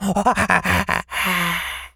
monkey_hurt_slow_death_02.wav